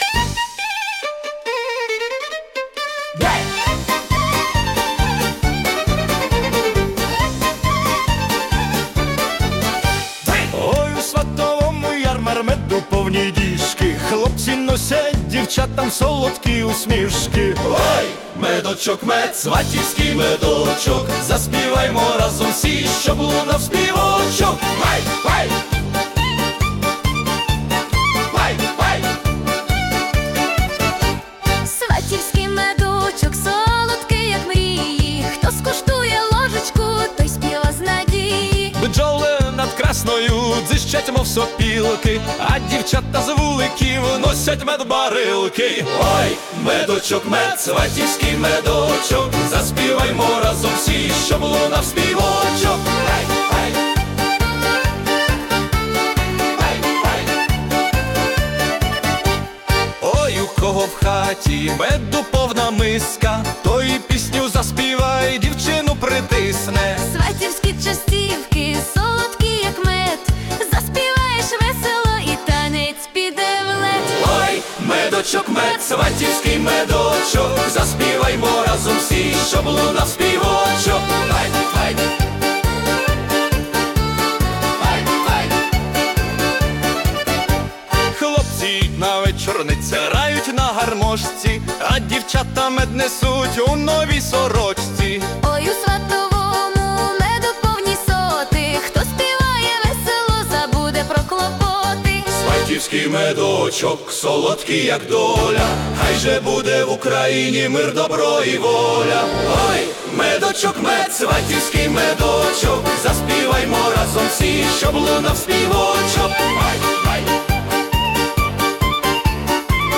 🎵 Жанр: Ukrainian Polka (Festive)
це запальна святкова полька (128 BPM)